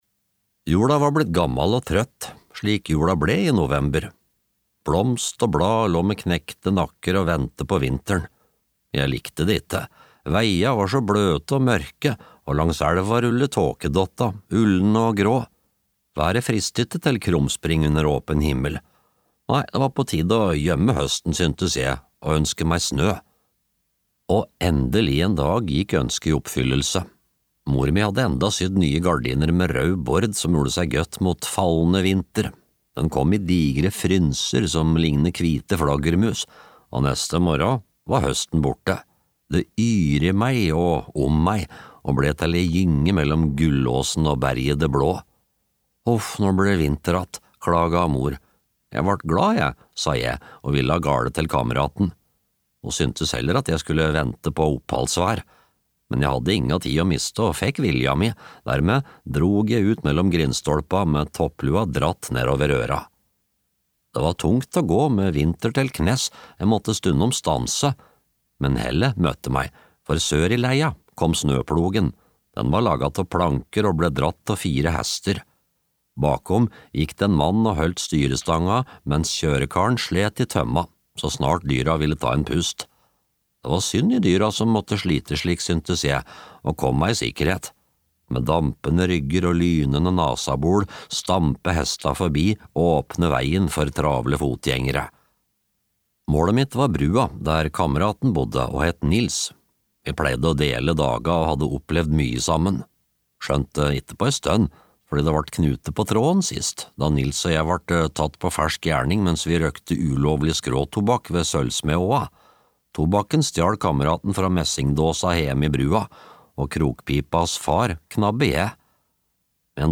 Mistenkelige fotspor (lydbok) av Vidar Sandbeck